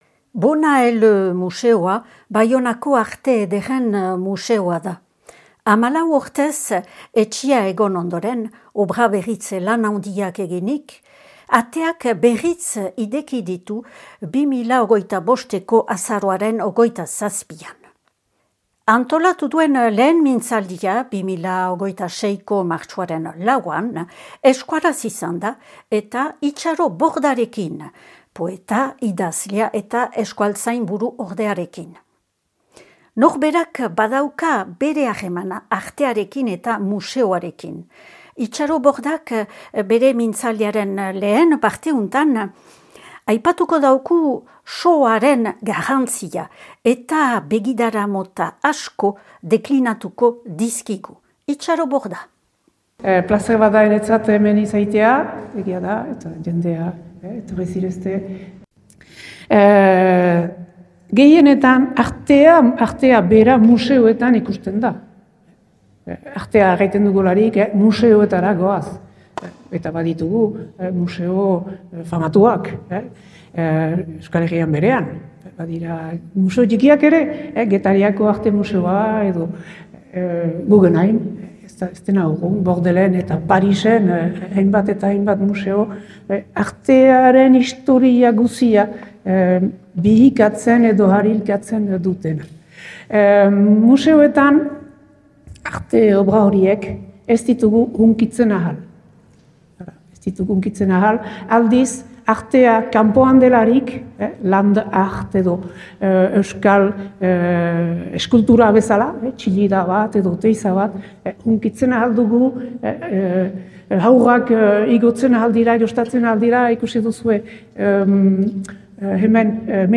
Baionako Arte Ederren museo berrituak antolatu lehen mintzaldia